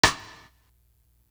Ray Snare.wav